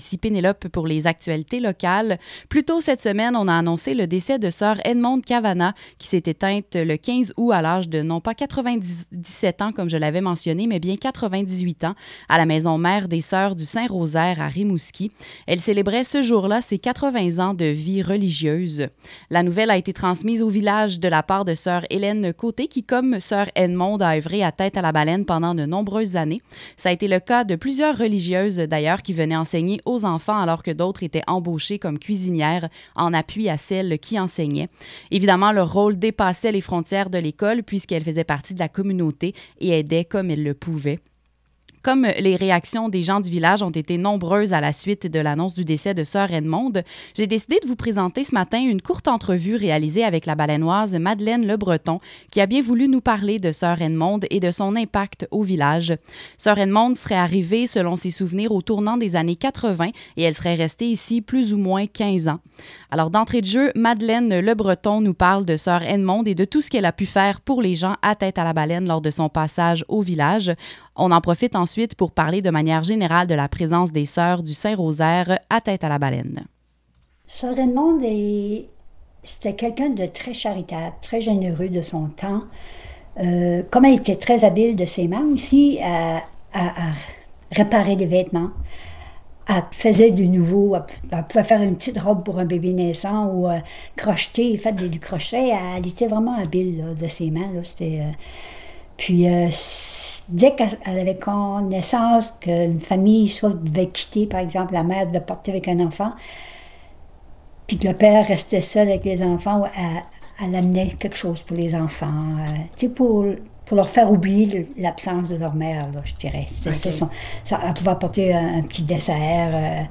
Pour écouter la nouvelle diffusée sur les ondes de CJTB :